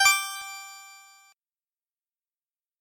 Звук удачного подключения